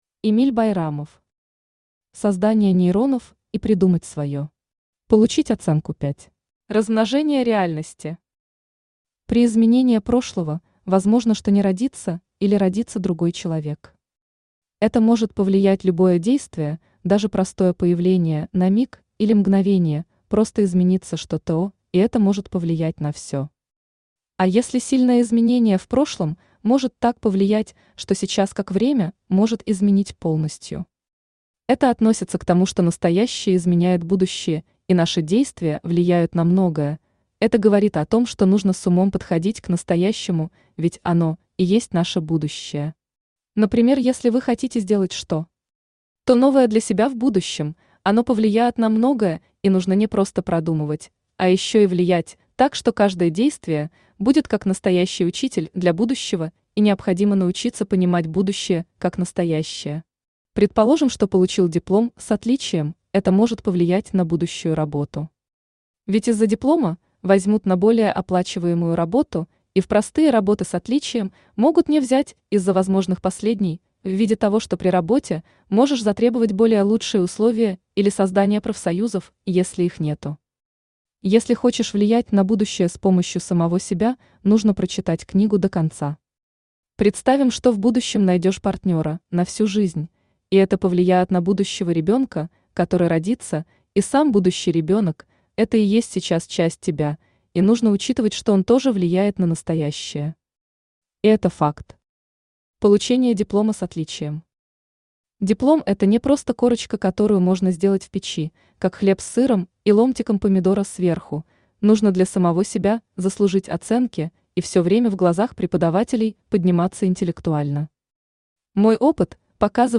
Аудиокнига Создание нейронов и придумать своё. Получить оценку пять | Библиотека аудиокниг
Получить оценку пять Автор Эмиль Бахдиярович Байрамов Читает аудиокнигу Авточтец ЛитРес.